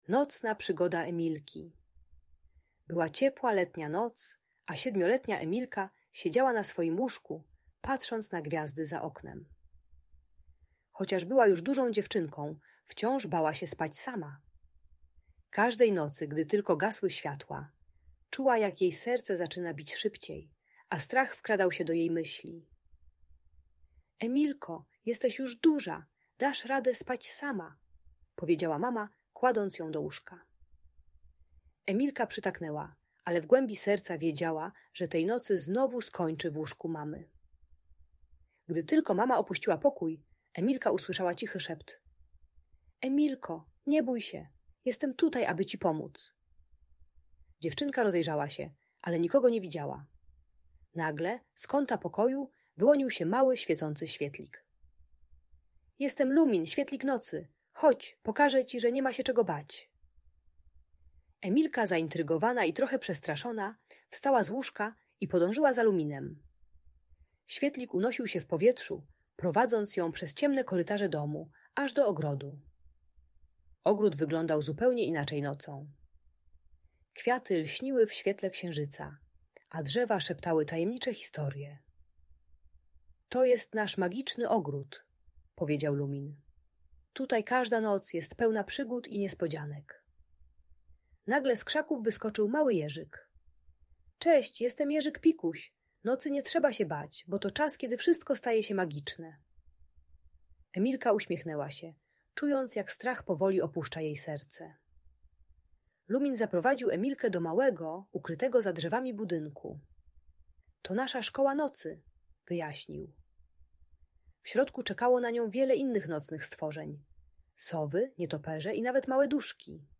Nocna Przygoda Emilki - Audiobajka